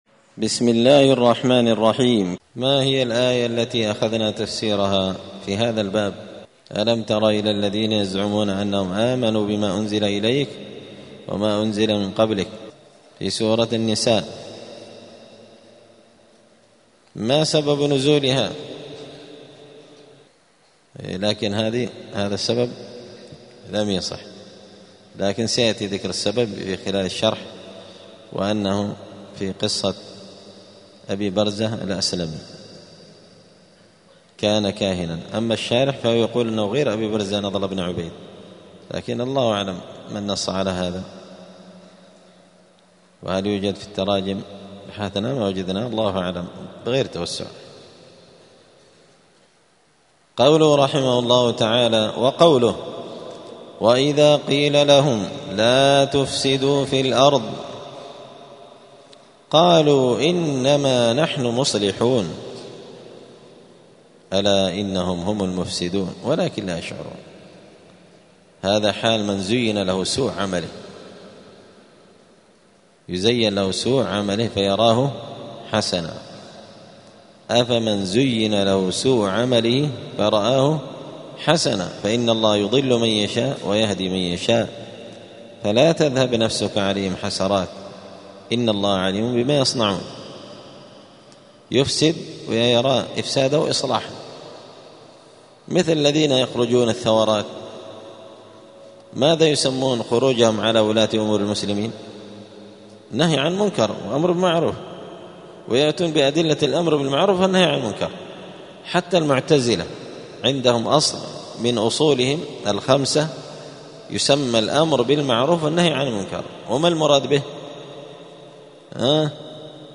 دار الحديث السلفية بمسجد الفرقان قشن المهرة اليمن
111الدرس-الحادي-عشر-بعد-المائة-من-كتاب-حاشية-كتاب-التوحيد-لابن-قاسم-الحنبلي.mp3